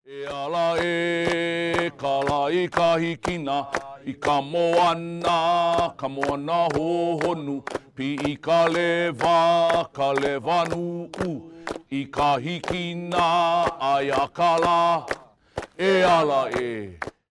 Listen to a Hawaiian chant by the Men of Pa’a from the film